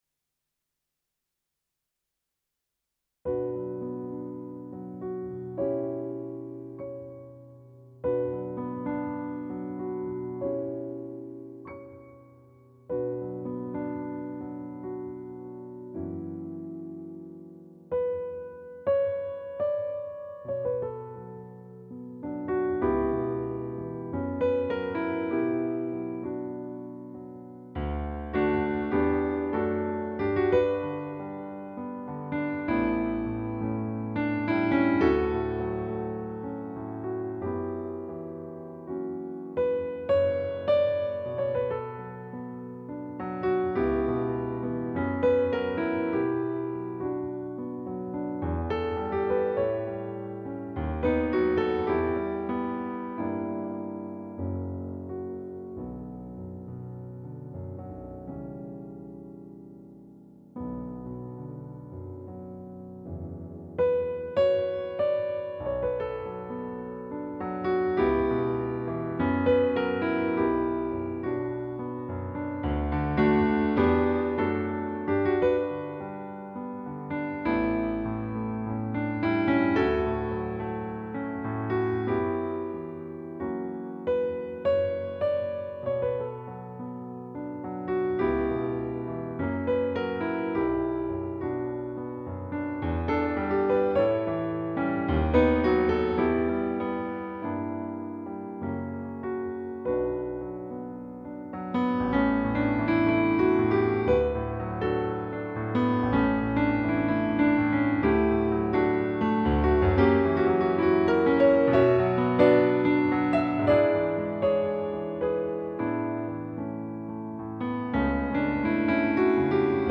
piano - romantique - melancolique - detente - melodique